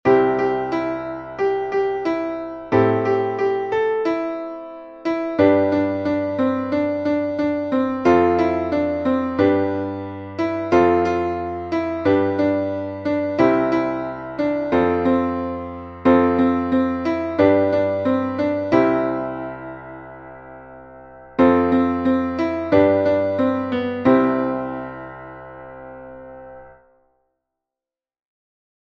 Kinder-/ Neujahreslied